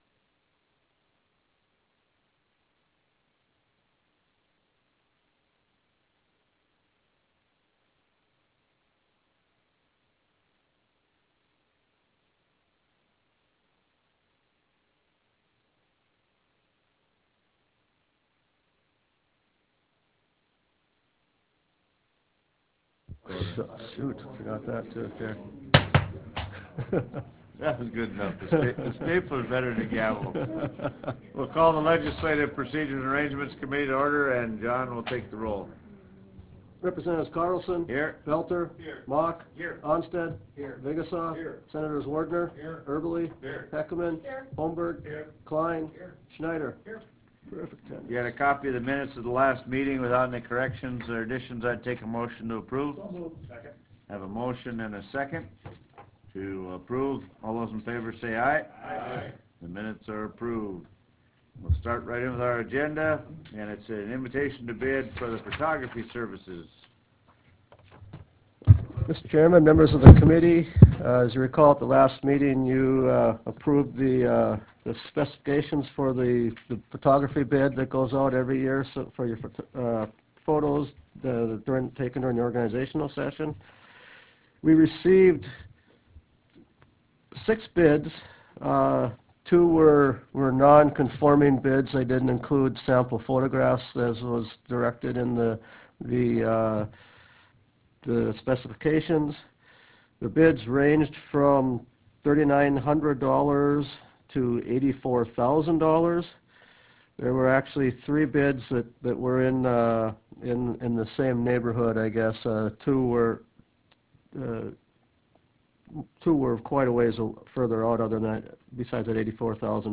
West Legislative Meeting Room Judicial Wing State Capitol Bismarck, ND United States